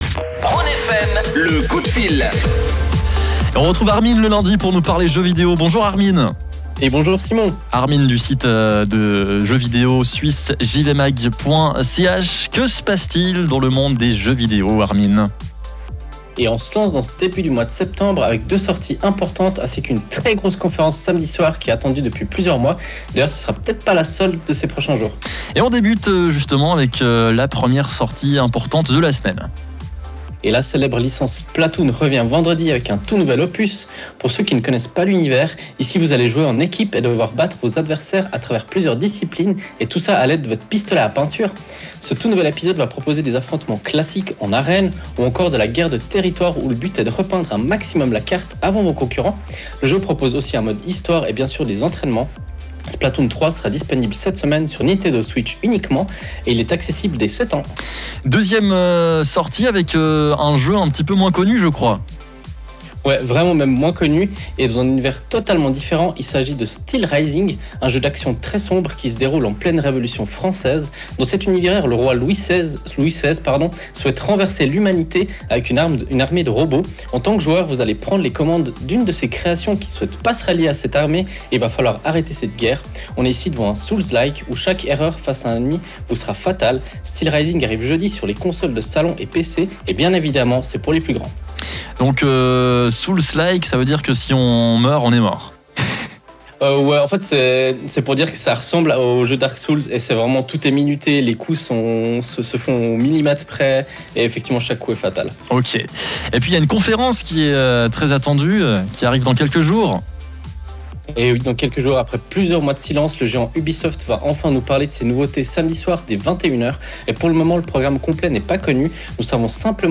À ce propos, voici notre direct du jour avec plusieurs sujets abordés. Deux sorties majeures avec SteelRising, ainsi que Splatoon 3 sont à retrouver ! On rappelle aussi le très attendu événement signé Ubisoft et nommé Forward, qui aura lieu samedi soir dès 21h.